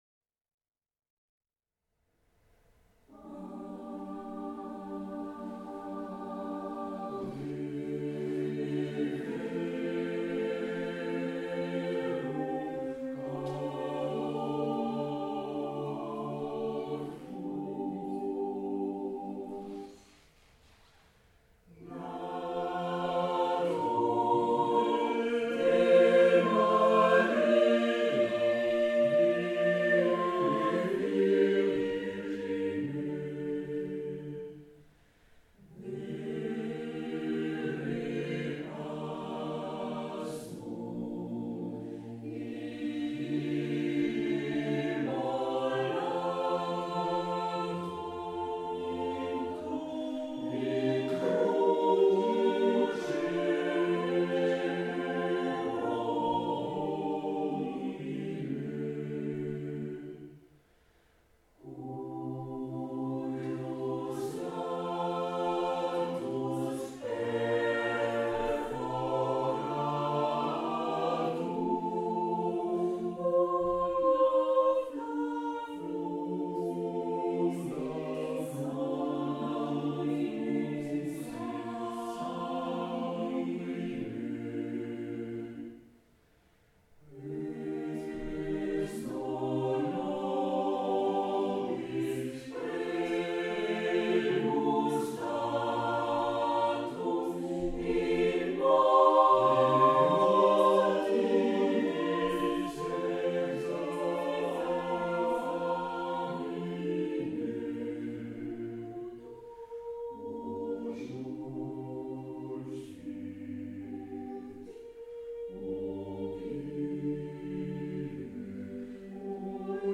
en sol mineur a capella à Bougy-Villars et à 440Hz.